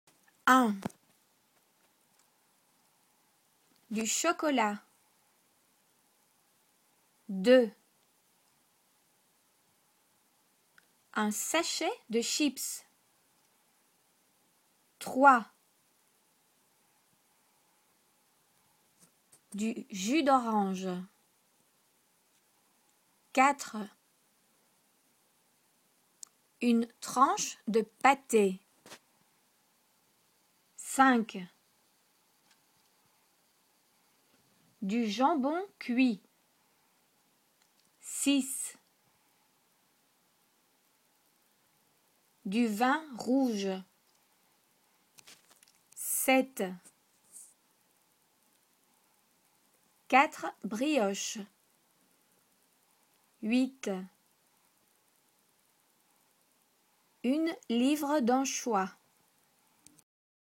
Le son [ʃ] et le son [ʒ]